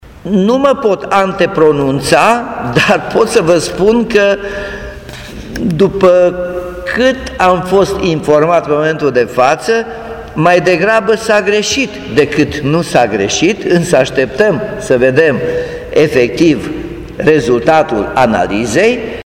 Primarul a mai spus că oamenii ar putea primi înapoi o parte din banii plătiți chirie, existând suspiciuni că majorarea chiriilor la cuantumul din prezent s-a realizat dintr-o eroare: